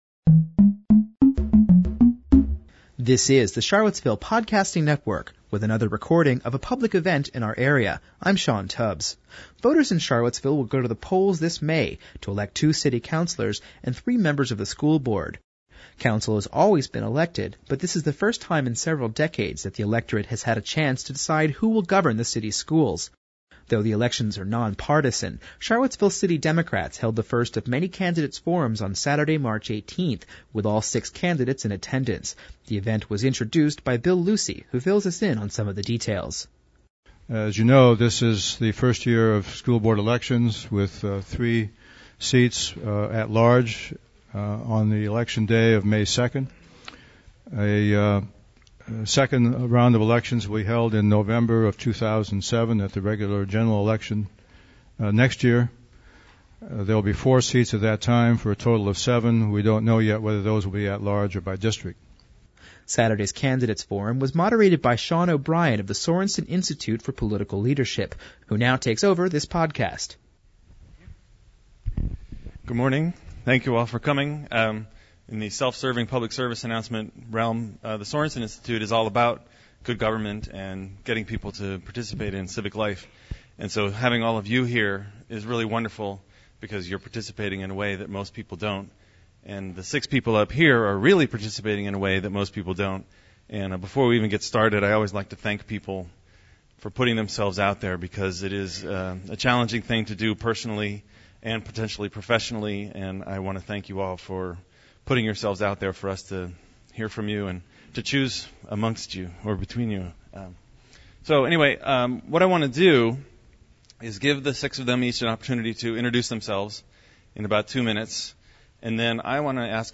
Though the elections are non-partisan, Charlottesville city Democrats held the first of many candidates forums on Saturday, March 18, with all six candidates in attendance.